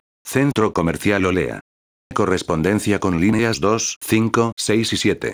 megafonias-react
1-centro_comercial_holea.wav